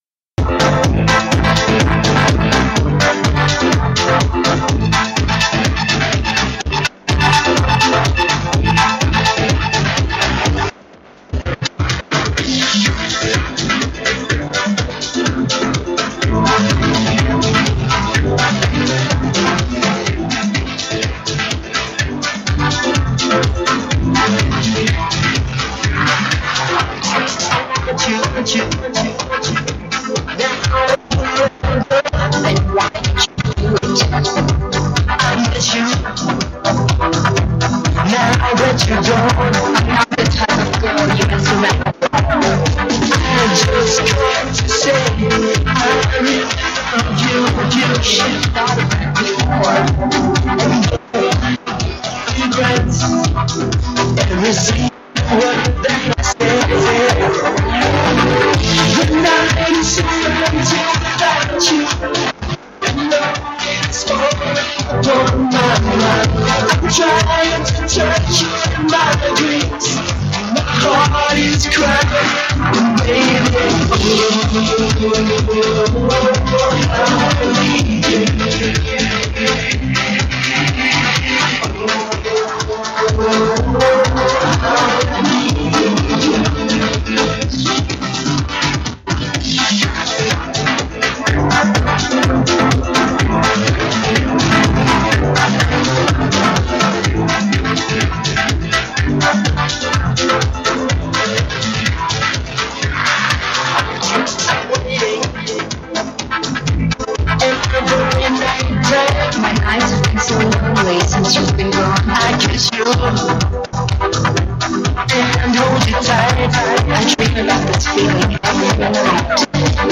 какой-то из многочисленных ремиксов этой песенки